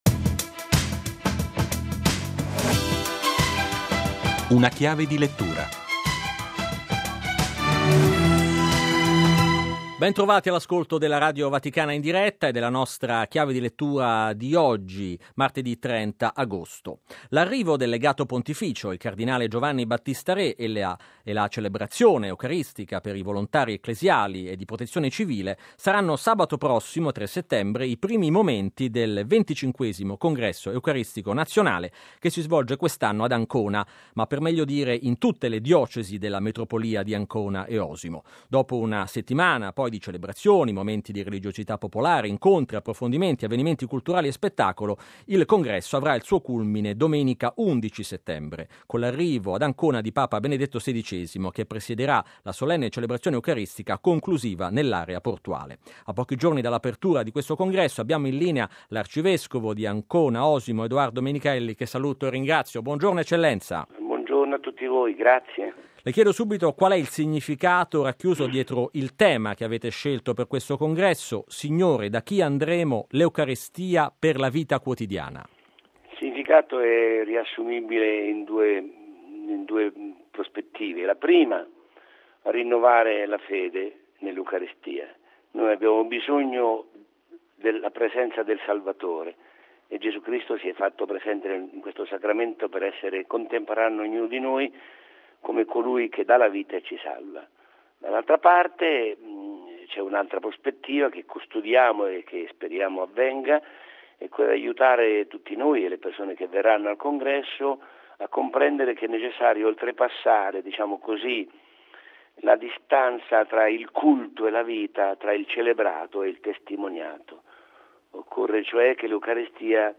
S.E. mons. Edoardo Menichelli, arcivescovo di Ancona-Osimo